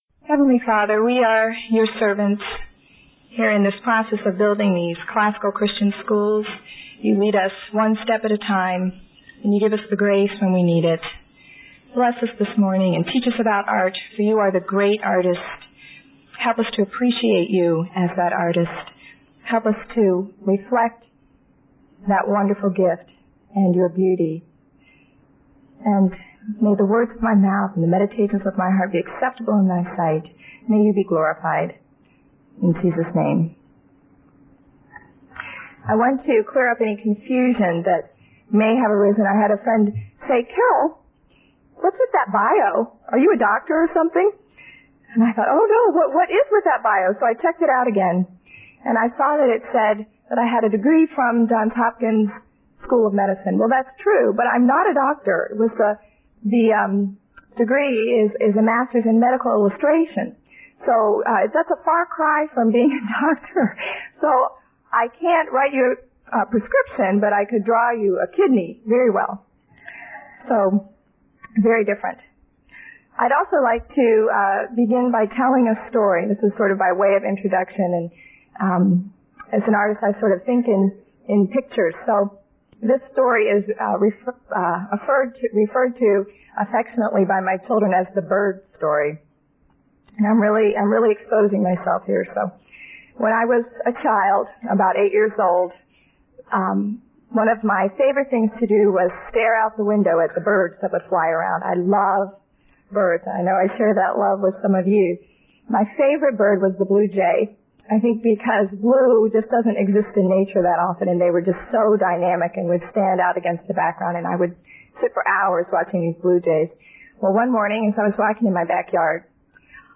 2003 Workshop Talk | 0:44:12 | All Grade Levels, Art & Music